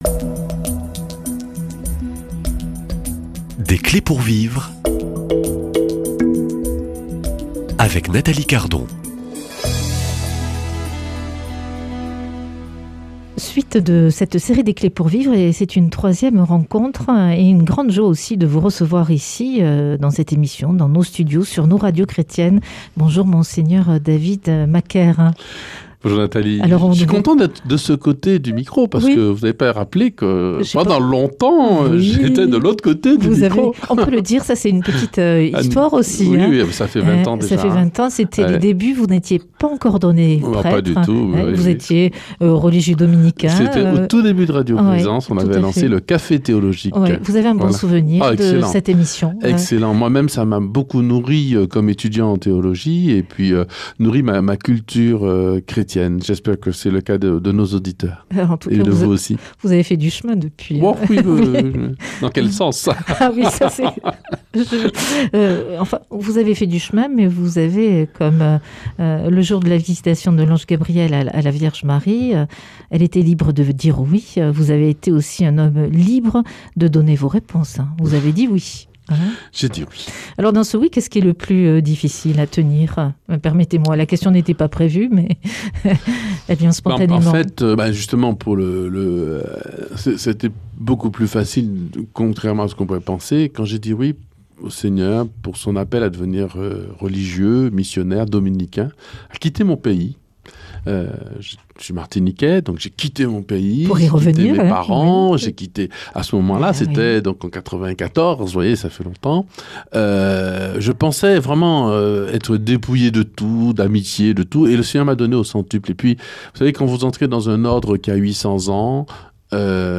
"Aimons nos prêtres" Ces pauvres types que Dieu a choisis (Volet 3) Invité : Mgr David Macaire, ordonné prêtre chez les Dominicains à Toulouse en 2001.